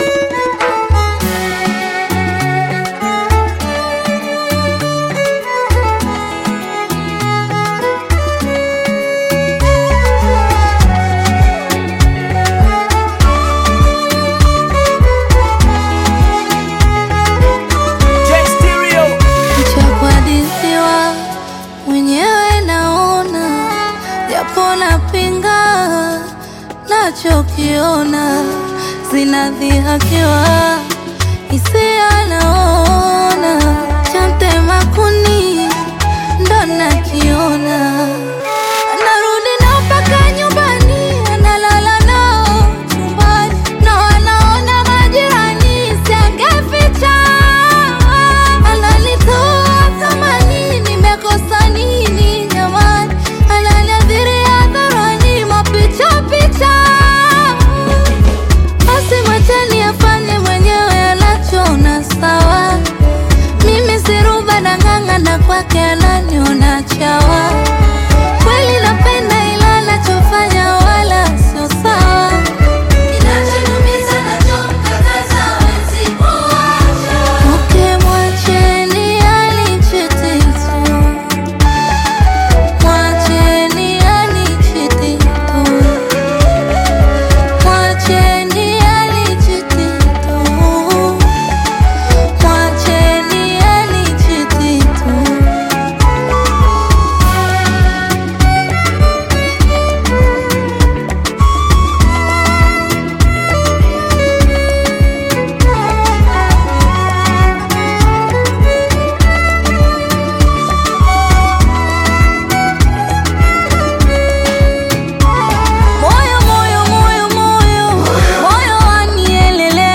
Bongo Flava
Tanzanian Bongo Flava artists, singers, and songwriters